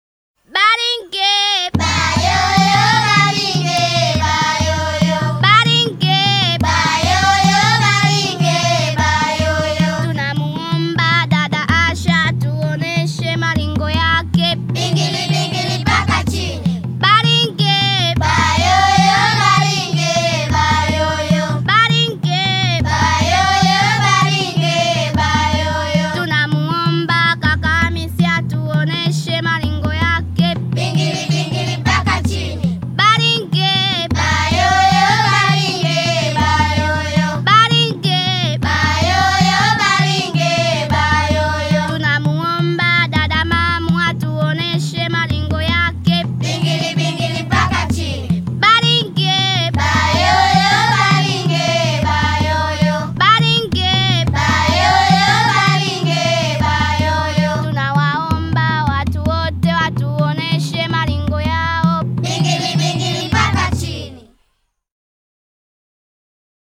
Kids rocken an Weihnachten auf Sansibar
Ukuti Ukuti Cover (c) daressalam.diploUkuti Ukuti Cover (c) daressalam.diplo Das Lied stammt aus dem wunderschönen Liederbuch Ukuti Ukutui von DCMA, Mkuku Na Nyota Publishers ltd. Darin findest du noch mehr Swahili-Kinderlieder mit CD und Liedtexten sowie lustigen Kinderzeichnungen.